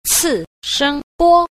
5. 次聲波 – cìshēngbō – thứ thanh ba (sóng hạ âm)